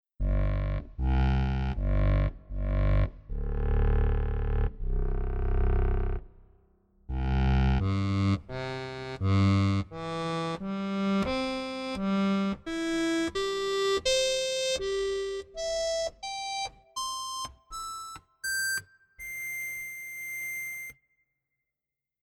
Je nach Lage variiert der Klang stark – von dezent röhrenden Bässen über Mitten, die nahe am Akkordion sind, bis hin zu etwas rauen Höhen mit angenehmer Patina.